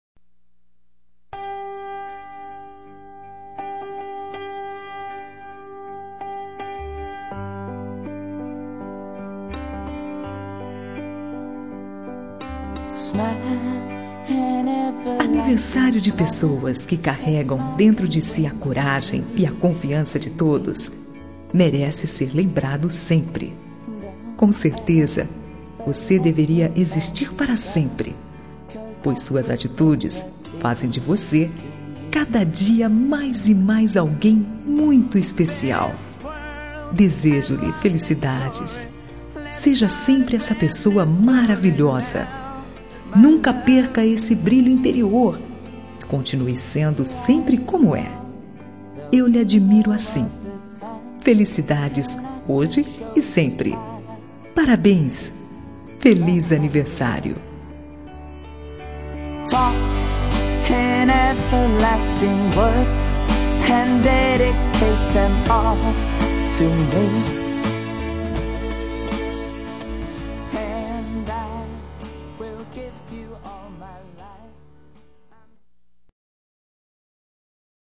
Telemensagem Aniversário de Amiga – Voz Feminina – Cód:1523